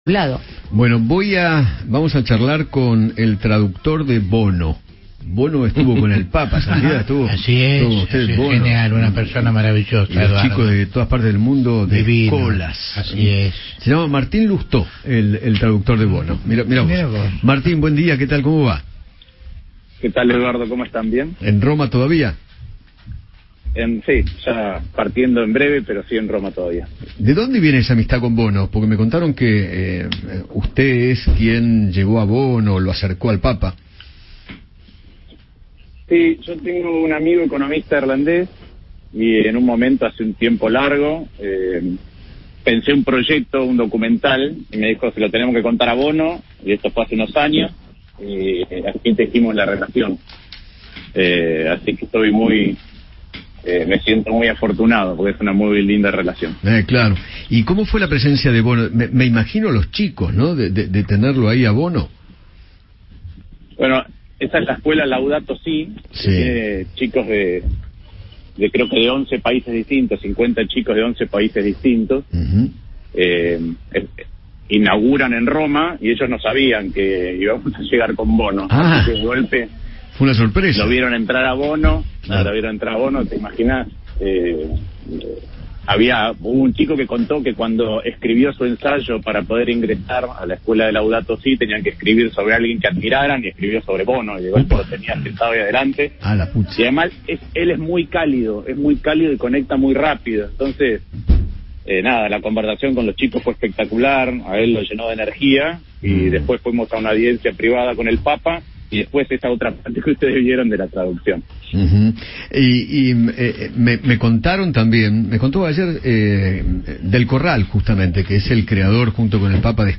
Eduardo Feinmann dialogó con el senador nacional Martín Lousteau sobre la reunión entre el papa Francisco y Bono, líder de la banda U2, en la que participó como traductor improvisado.